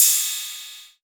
VEC3 Cymbals Ride 26.wav